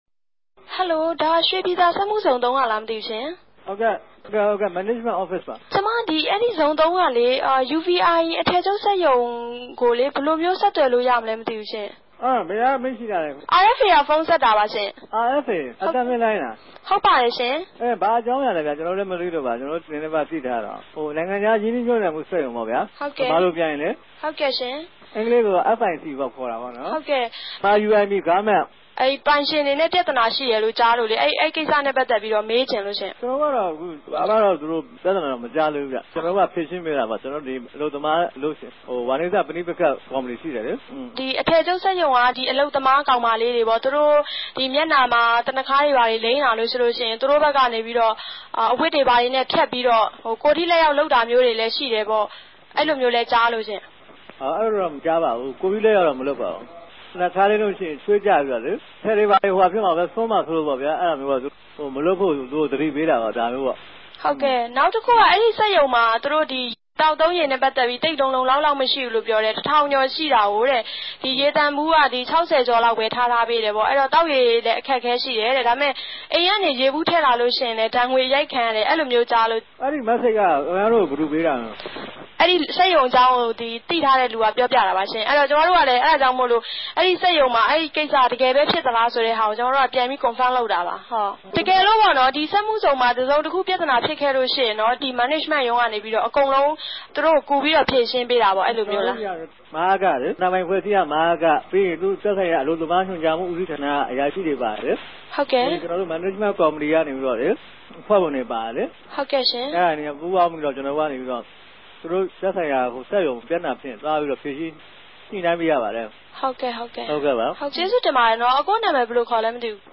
အလုပ်သမား အမဵိြးသမီး။       "အရင်က မိနစ်လေးဆယ် နားပေးတယ် အခုက နာရီဝက်ပဲပေးတယ် သိပ်မုကာသေးဘူး ိံြစ်ရက်သုံးရက်လောက်ပဲရြိသေးတယ် ဋ္ဌကီးဋ္ဌကီးကဵယ်ကဵယ် ကတော့ ရေကိင်္စပဲပေၝ့၊ ရုံထဲမြာ လူတေကြ အမဵားဋ္ဌကီးဆိုတော့ ရေသန်ႛပုံးက တနေႛမြ ပုံး၆၀လောက်ပဲ မြာတယ် ကဵန်တဲ့ရေက ရေသန်ႛမဟုတ်ဘူးပေၝ့ မသန်ႛတဲ့ရေတြေ တိုက်တယ်။ အဓိကကတော့ ကဵန်းမာရေးရော လူမူရေးရော ဘာမြ မရြိဘူးပေၝ့။"